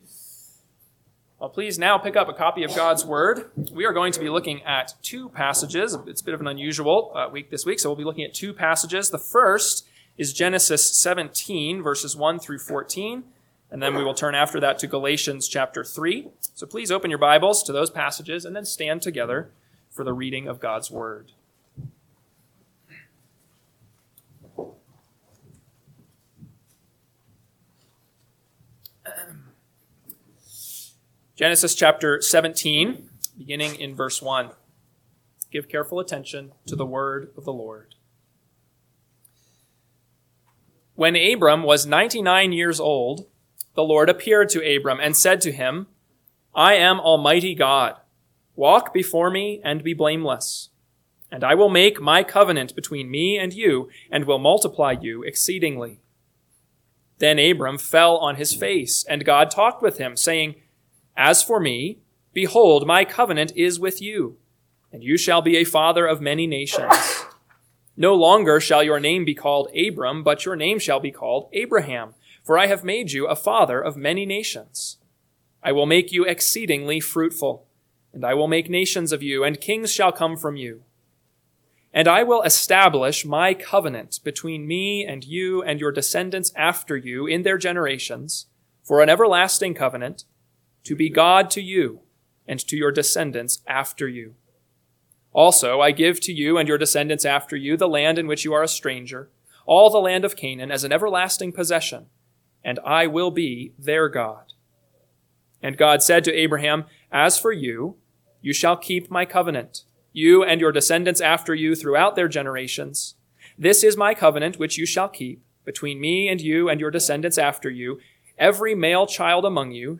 AM Sermon – 7/20/2025 – Genesis 17:1-14; Galatians 3:27-29 – Northwoods Sermons